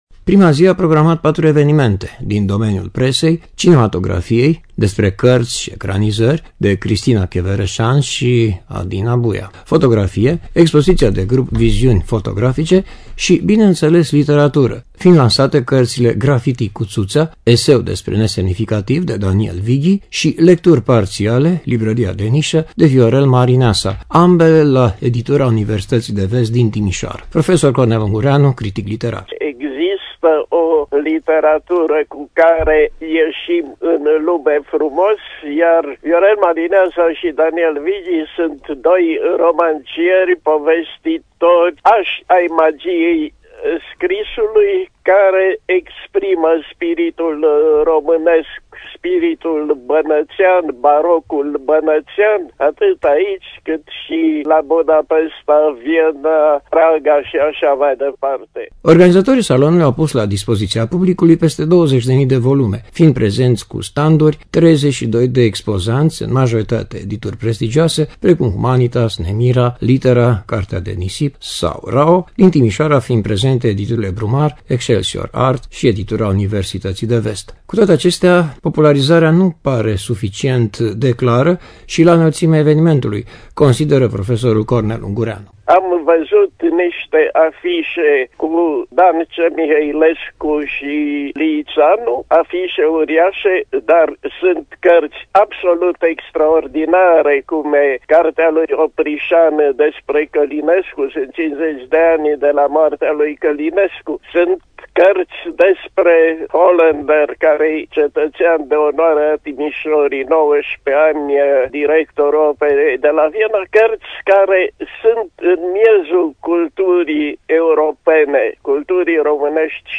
”Vestul zilei” – în direct de la Salonul de carte ”Bookfest”